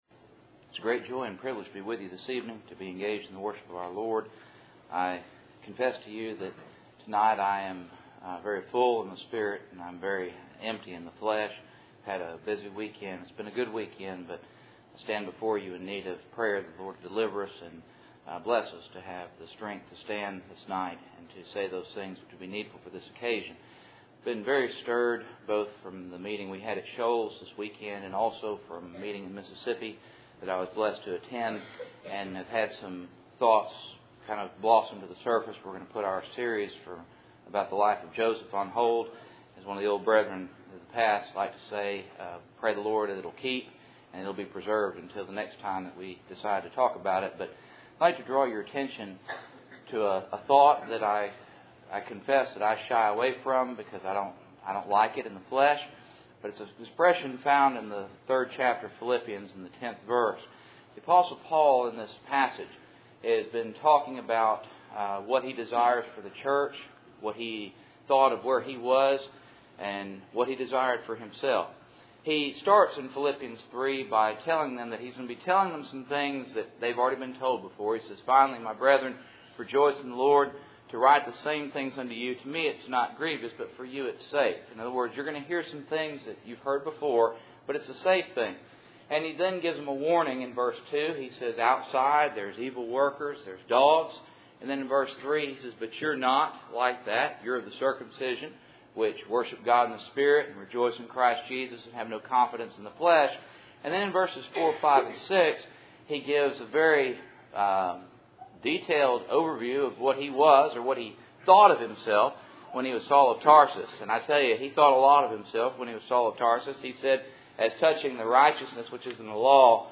Philippians 3:10-12 Service Type: Cool Springs PBC Sunday Evening %todo_render% « Joseph